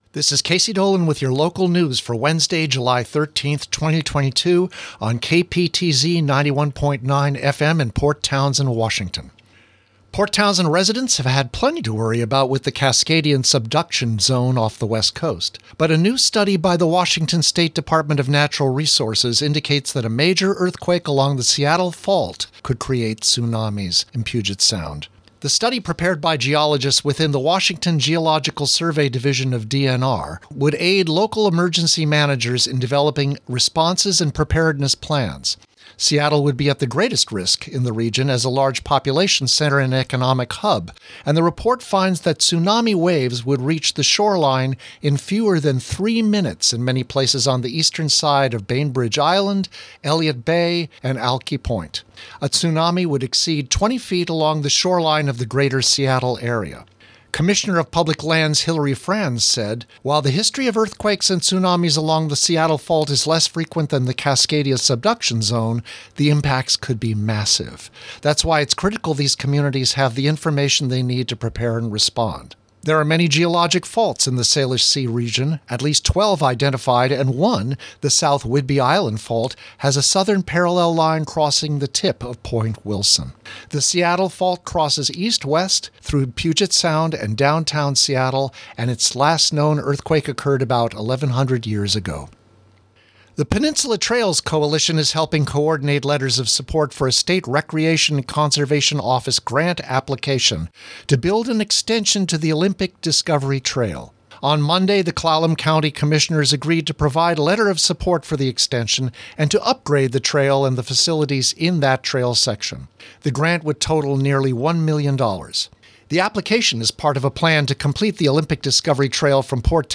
220713 Local News Wed